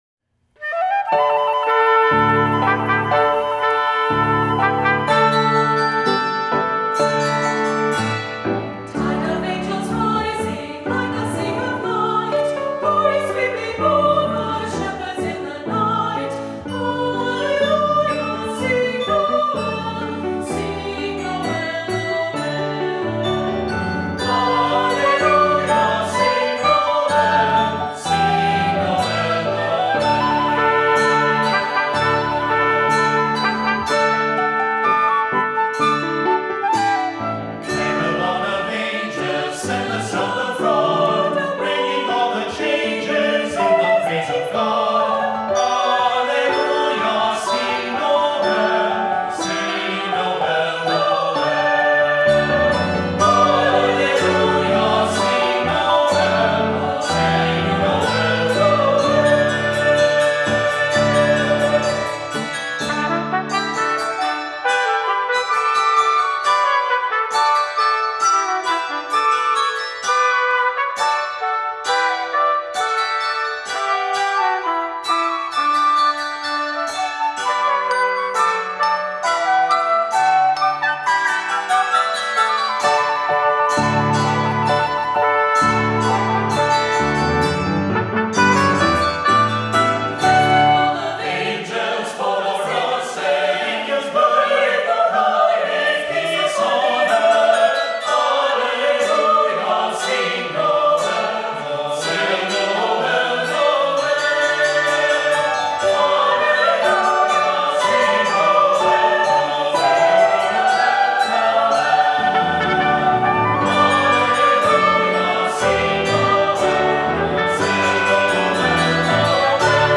Voicing: Unison ChildrenÕs Choir,Assembly,SATB